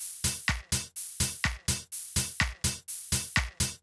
Drumloop 125bpm 01-B.wav